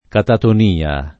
catatonia [ kataton & a ] s. f. (med.)